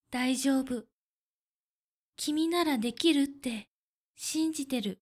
ボイス
キュート女性
josei_zibunwoshinziteyattemiru-1.mp3